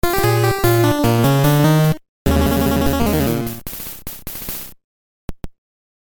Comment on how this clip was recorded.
This is a sample from a copyrighted musical recording.